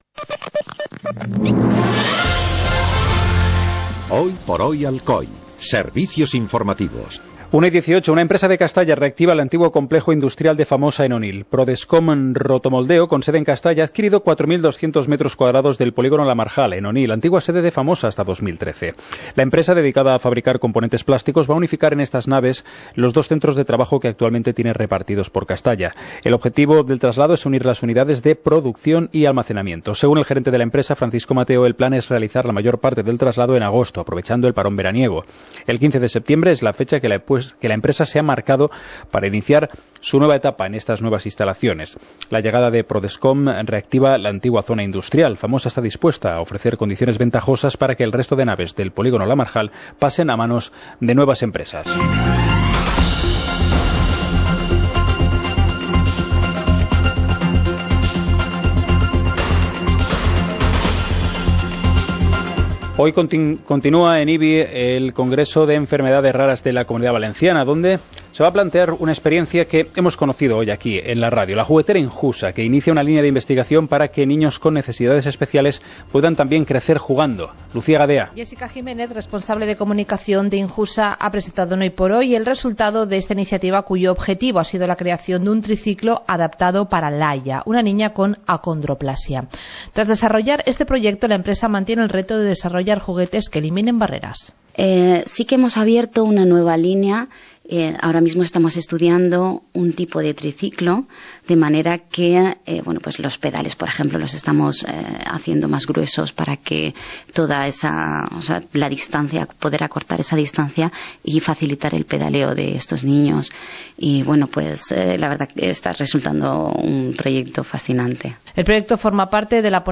Informativo comarcal - viernes, 03 de junio de 2016